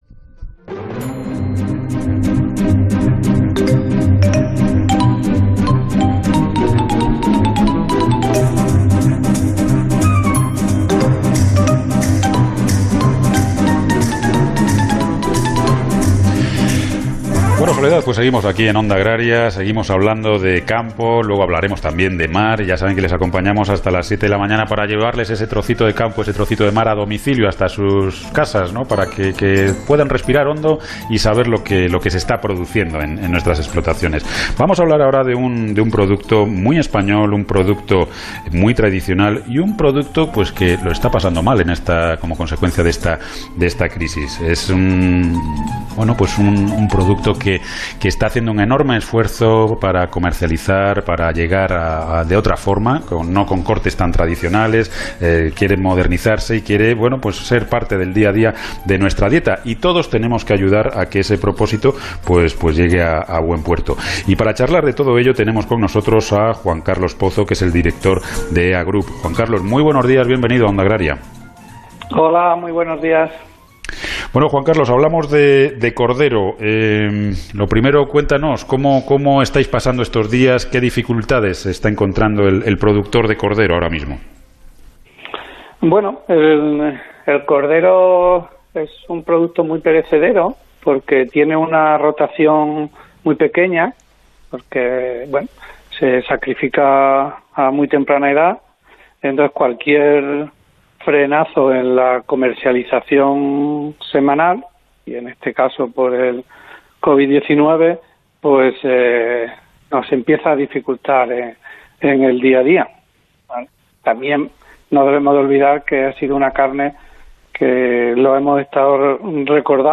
hablando en el programa Onda Agraria acerca del cordero